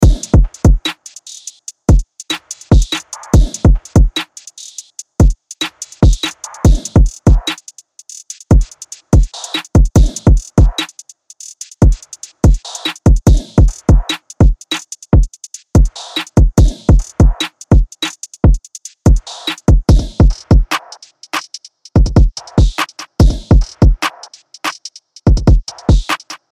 アトランタスタイルの808ベースとトラップビートが弾けるリズムサウンドを解き放つ
・力強いトラップ・ビートとチューニングされた808が、磨き上げられた荒々しさを湛える
プリセットデモ